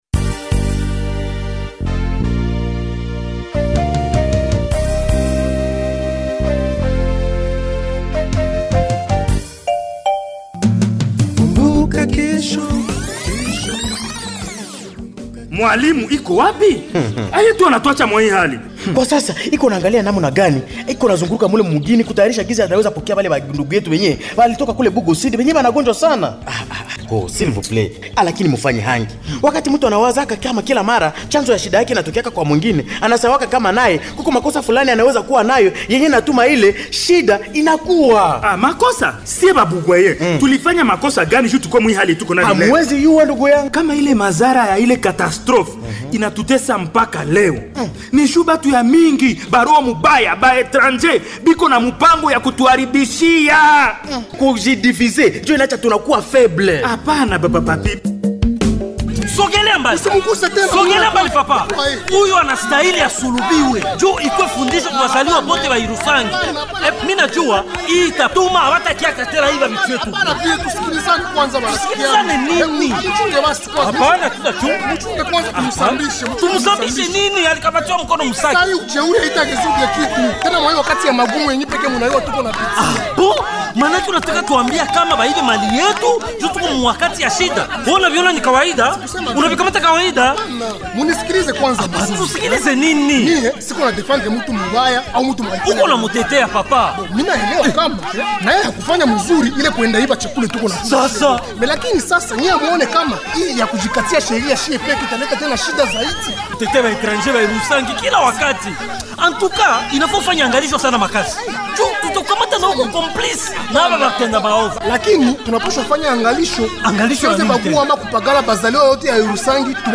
Voici le 978e numéro du feuilleton Kumbuka Kesho du 16 au 22 mars 2026